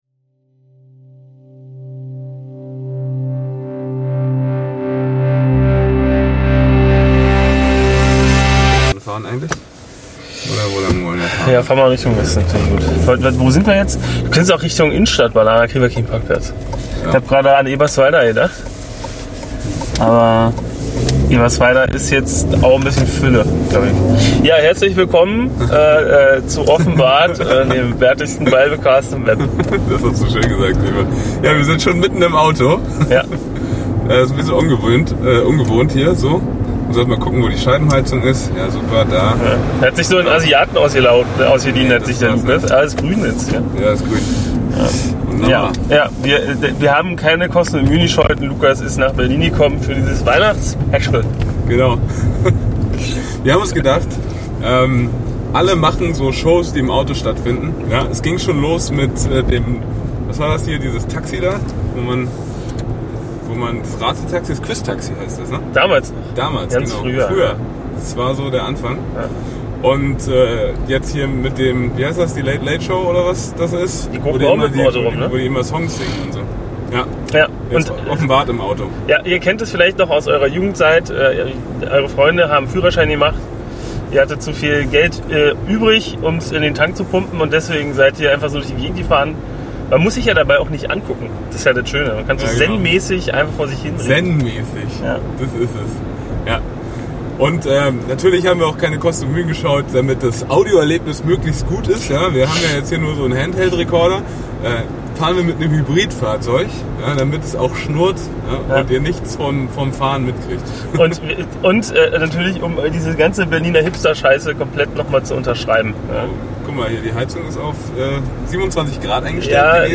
Diesmal direkt von den Straßen Berlins.